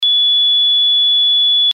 ZUMBADOR CON 8 SONIDOS
Zumbador Electrónico Empotrable para cuadro Ø 22,5MM
dB 86-100